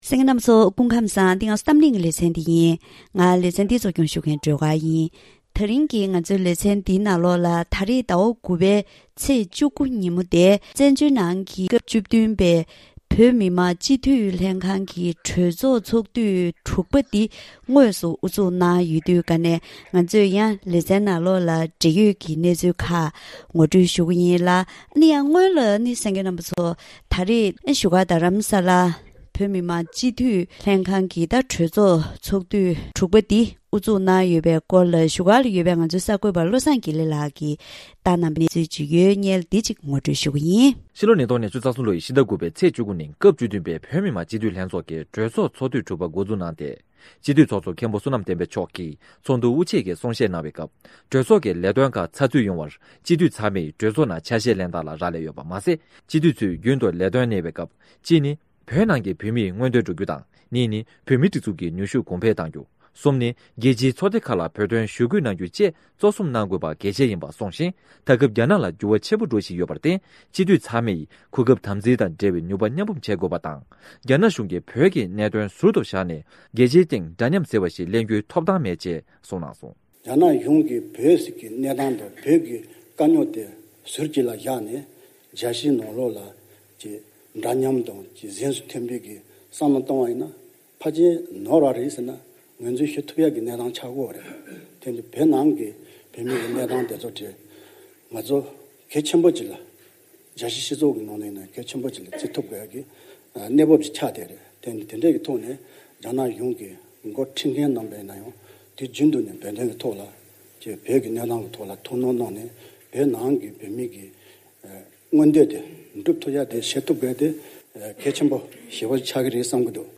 ད་རིང་གི་གཏམ་གླེང་ལེ་ཚན